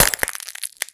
HauntedBloodlines/STEPS Glass, Walk 01, Loud Beginning.wav at main
High Quality Footsteps / Glass Enhancement
STEPS Glass, Walk 01, Loud Beginning.wav